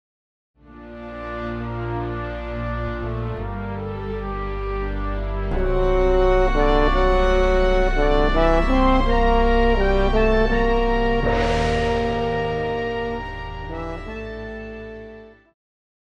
Pop
French Horn
Band
Instrumental
World Music,Electronic Music
Only backing